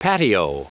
Prononciation du mot patio en anglais (fichier audio)
Prononciation du mot : patio